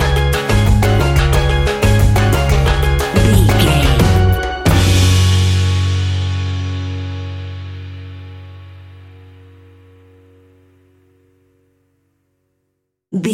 Ionian/Major
E♭
steelpan
happy
drums
bass
brass
guitar